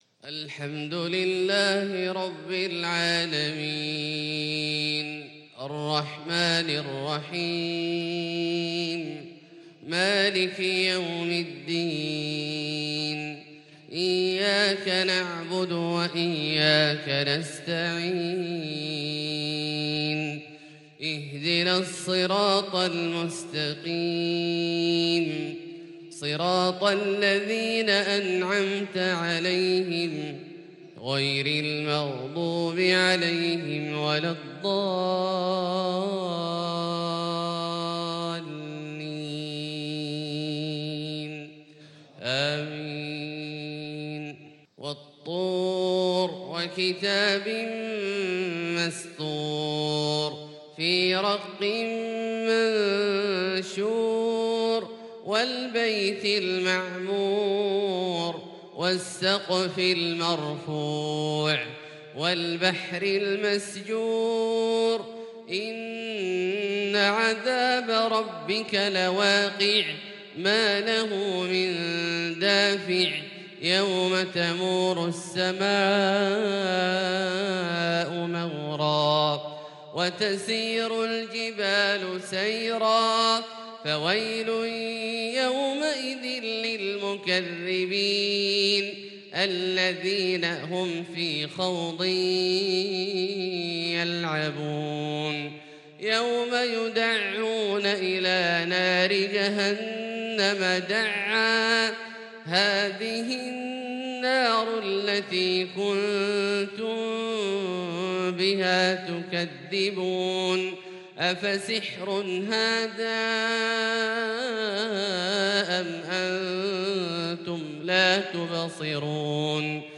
صلاة الفجر للقارئ عبدالله الجهني 7 جمادي الأول 1442 هـ
تِلَاوَات الْحَرَمَيْن .